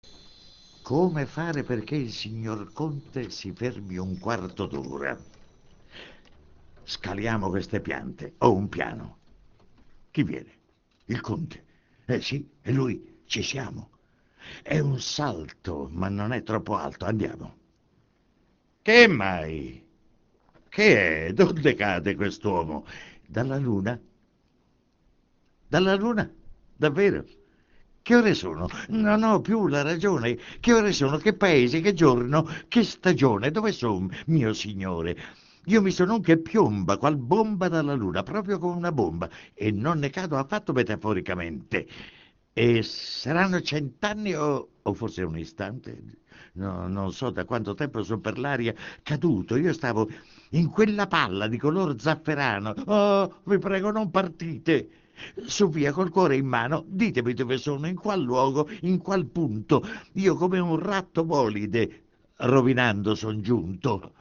Per voi le più prestigiose interpretazioni del Maestro Arnoldo Foà